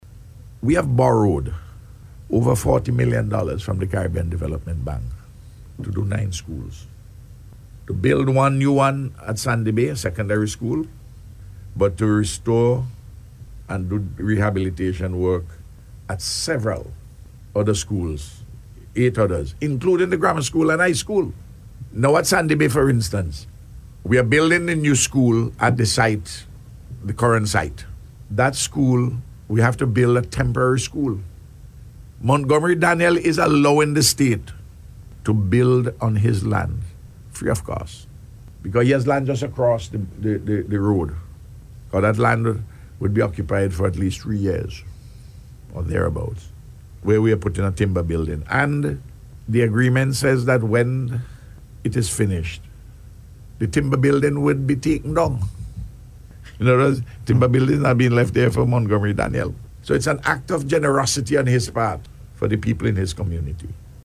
Prime Minister Gonsalves said on Radio recently that the project will include the complete reconstruction and upgrade of nine schools.